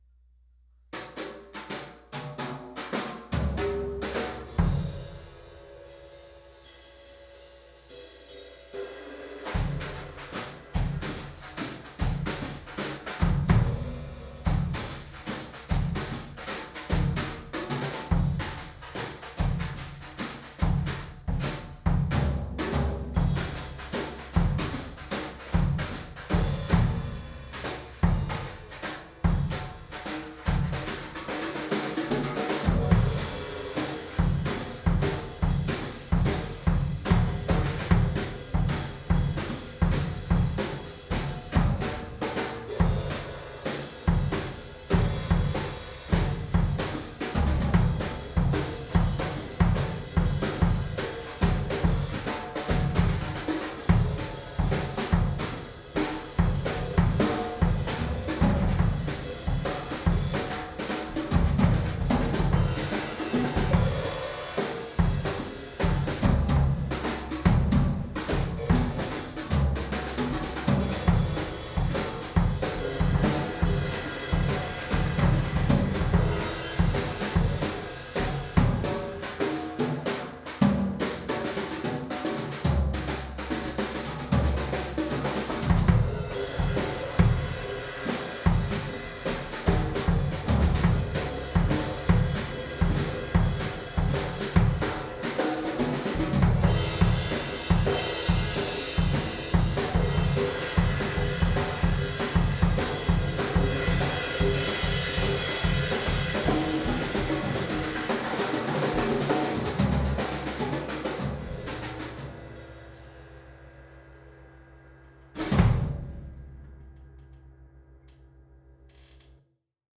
Percussion Improv